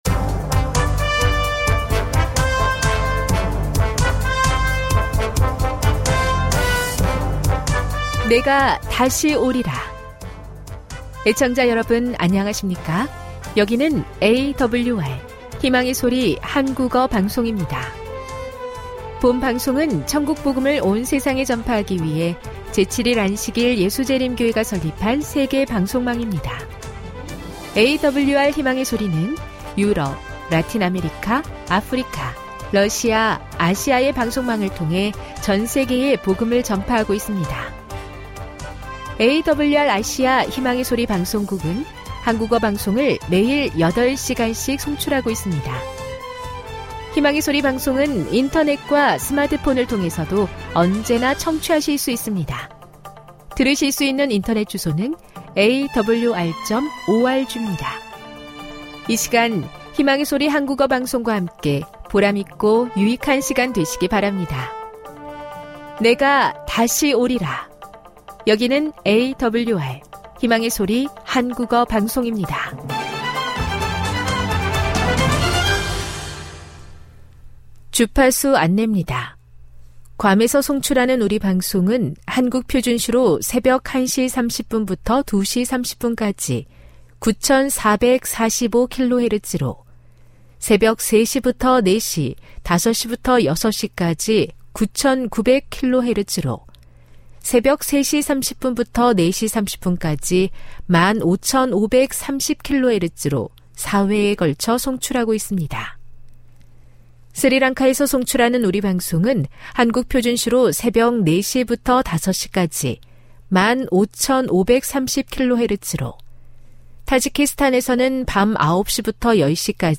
1 설교, 명상의오솔길, 생명의 양식 58:43